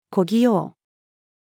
小器用-female.mp3